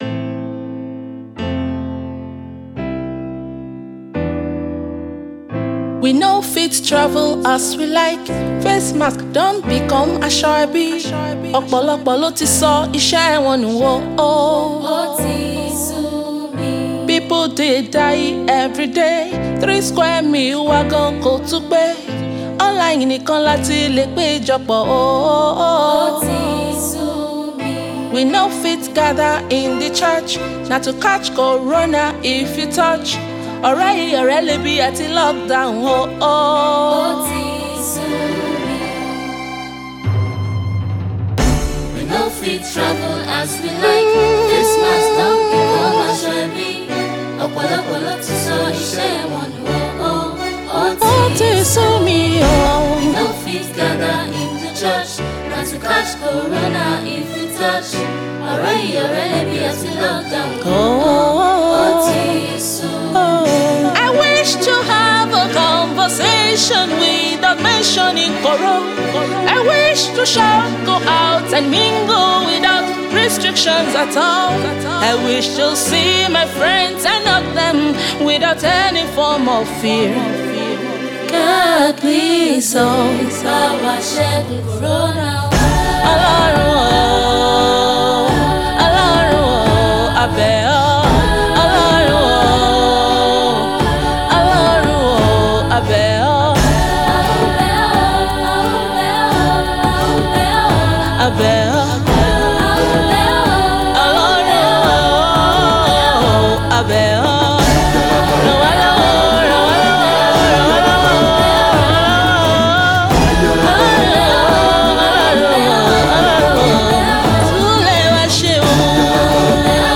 short aesthetic track
thrilling instrumental and vocal arrangements